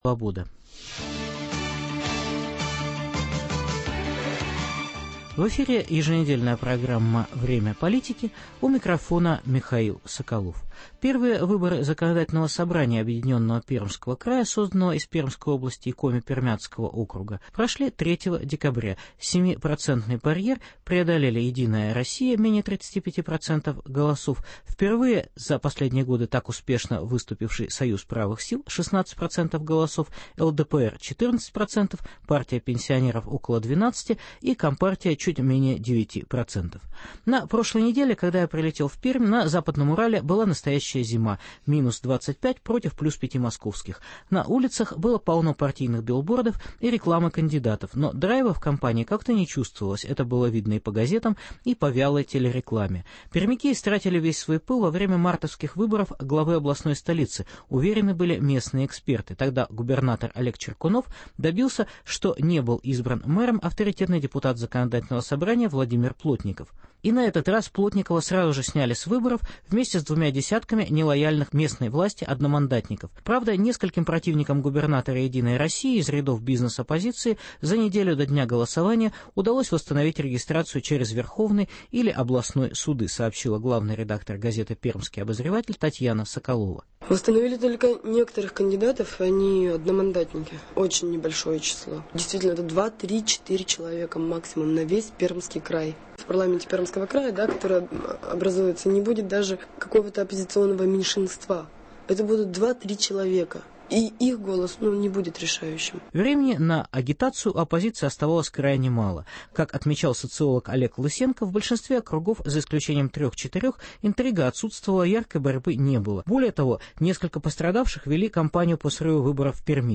Итоги выборов Законодательного собрания Пермского края: относительный неуспехи "партии власти" на фоне роста рейтинга СПС и ЛДПР. Специальный репортаж со съезда "Единой России".